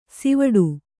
♪ sivaḍu